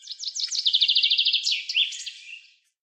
На этой странице собраны натуральные звуки чириканья птиц в высоком качестве.
Рассветное пение птицы